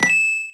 Sound effect of "Coin" in Super Smash Bros. Melee.